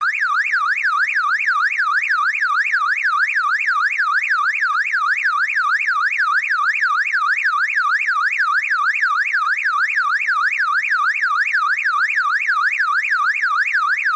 Звуки брелка сигнализации